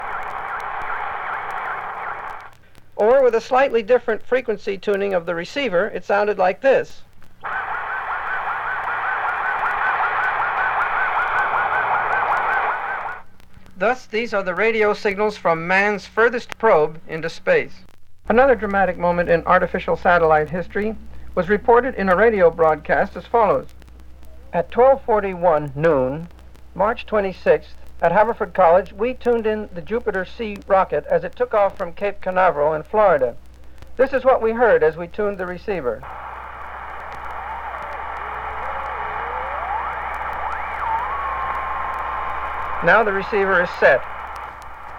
58年に打ち上げられた米ソの13機からの音。宇宙へ飛び立った犬、ライカの心音も。多くのフィールドレコーディング同様に、刺激溢れる音の世界です。
Field Recording, Non Music　USA　12inchレコード　33rpm　Mono